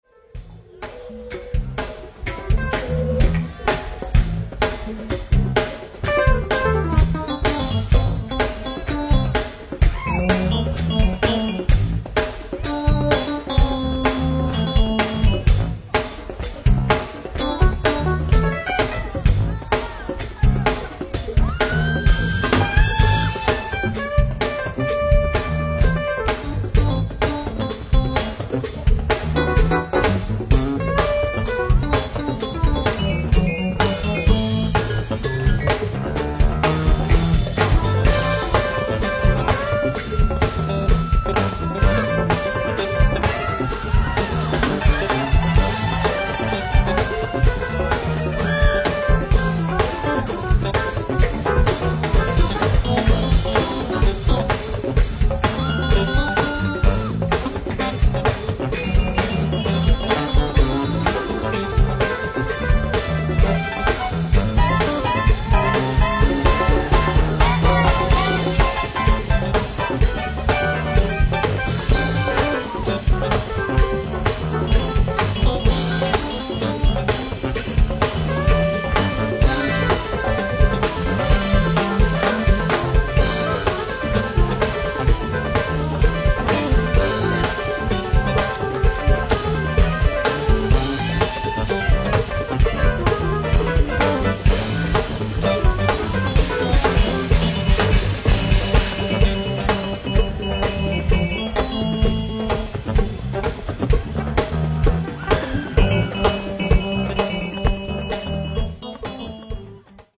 ライブ・アット・ボナルー・フェスティバル、マンチェスター、テネシー 06/10/2005
ステレオ・サウンドボード収録！！
※試聴用に実際より音質を落としています。